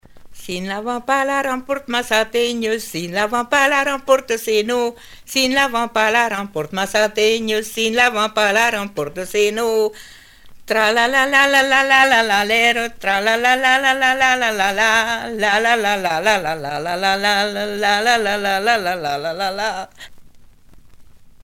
danse : branle
Pièce musicale éditée